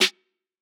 Snare Wake Up.wav